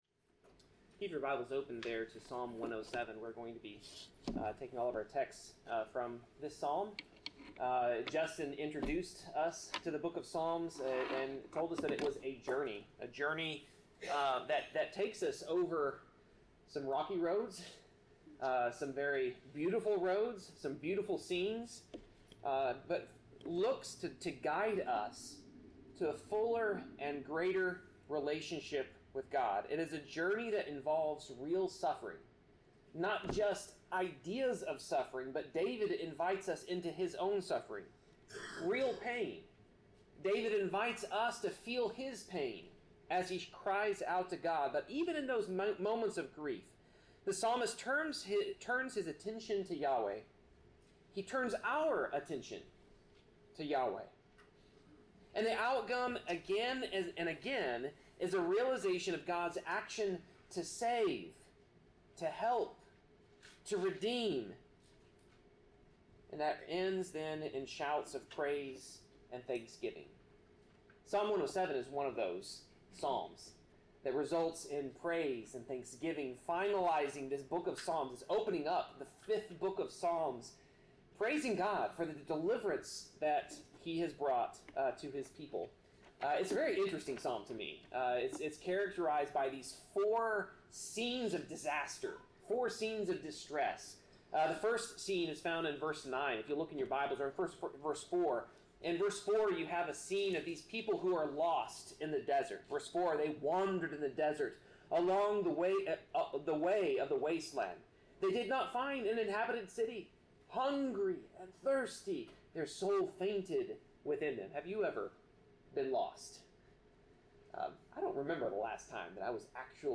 Passage: Psalm 107 Service Type: Sermon